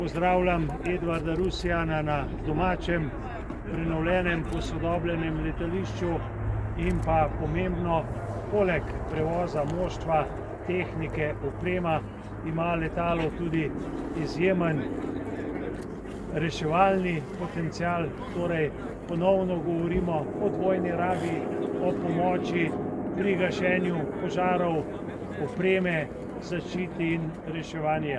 Slavnostni govornik ob prihodu drugega letala C-27J Spartan v Slovenijo minister za obrambo mag. Borut Sajovic
Minister za obrambo Borut Sajovic o drugem taktičnem transportnem letalu spartan